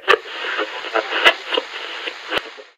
radio_mixdown_3.ogg